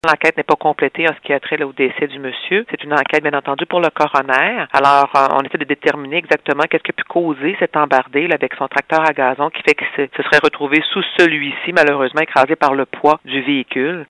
comme le précise la porte-parole